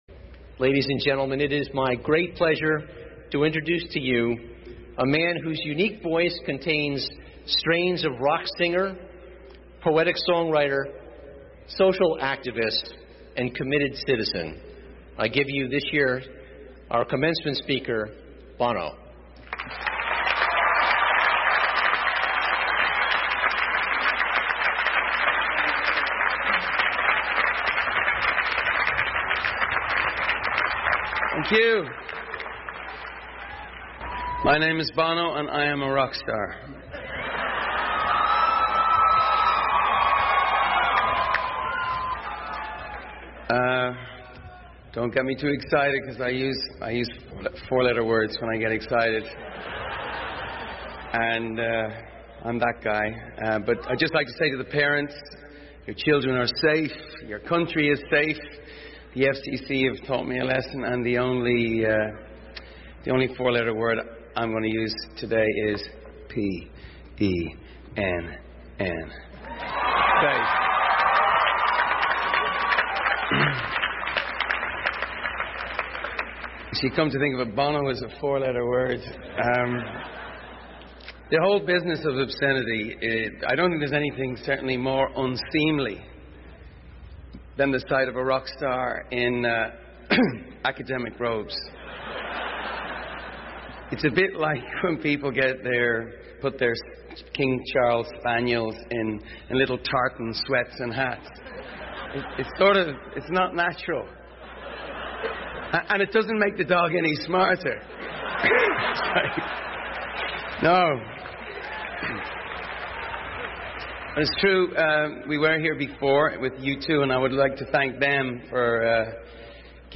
The following is the full transcript of U2 lead singer Bono’s 2004 commencement speech at the University of Pennsylvania.